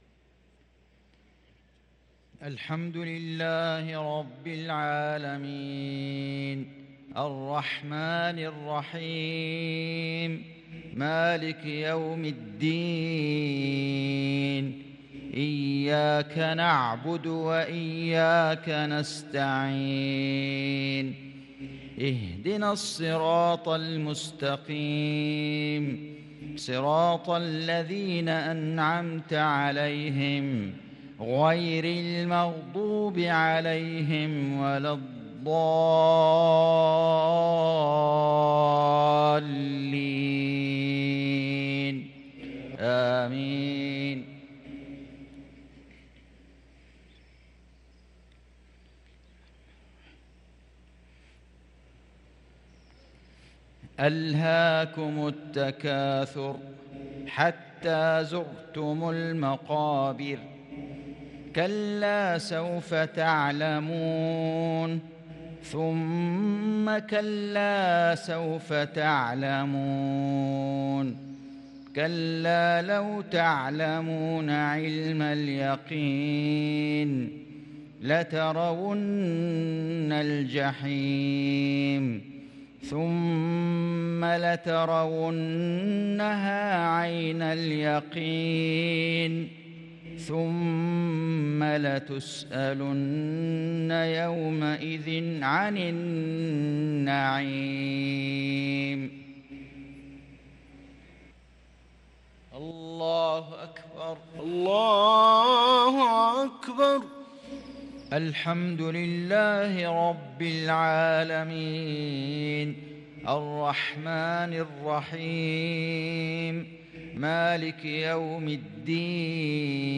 صلاة المغرب للقارئ فيصل غزاوي 13 ربيع الأول 1444 هـ
تِلَاوَات الْحَرَمَيْن .